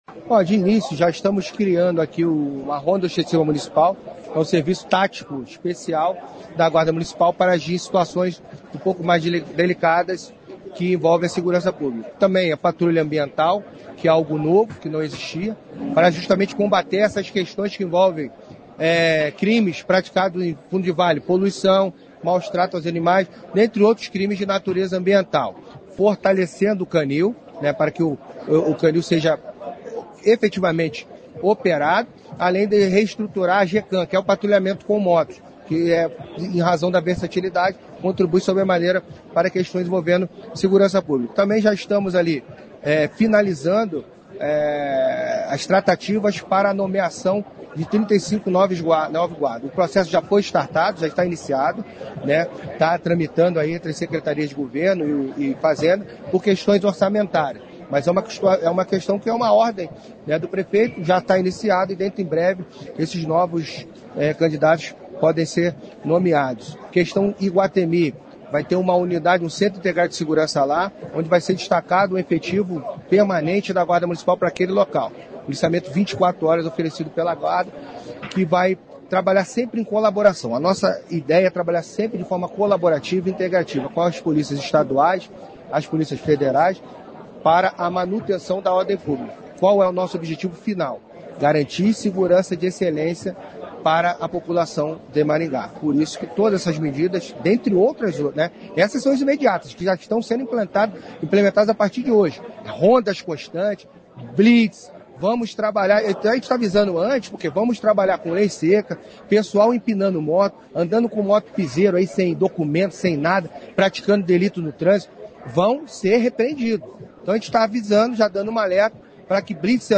E as ações incluem patrulhamento e blitz constantes. Há previsão de um posto da Guarda em Iguatemi, rondas com uso de patinetes, entre outras ações. Ouça a entrevista: